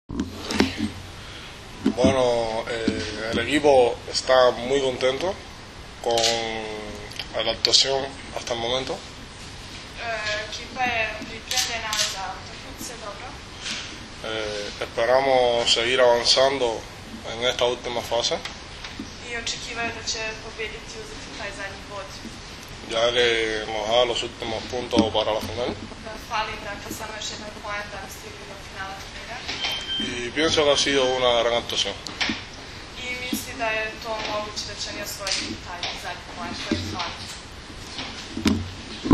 Danas je u Medija centru SPC „Vojvodina“ u Novom Sadu održana konferencija za novinare, kojoj su prisustvovali kapiteni i treneri Kube, Srbije, Rusije i Japana
IZJAVA VILFREDA LEONA